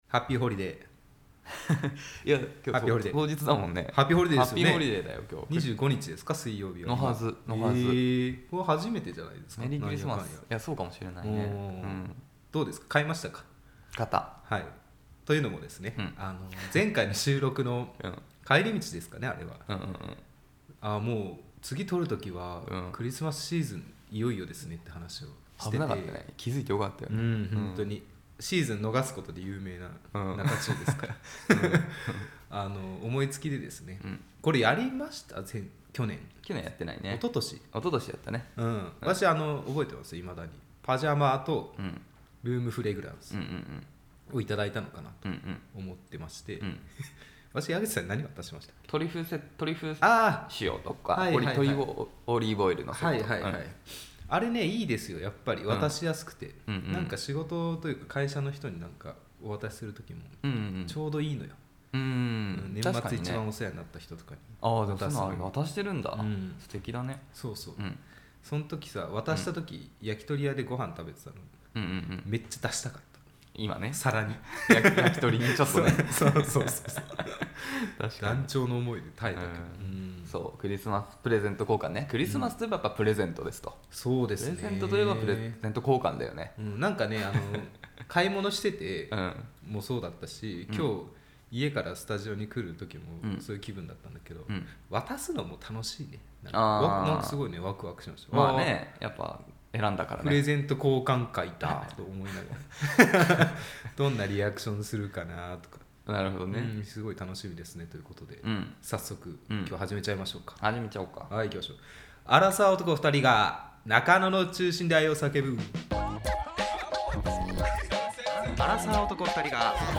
恋の街 中野よりアラサー男が恋愛トークをお届けします！ あなたの恋のお悩み、聞かせてください！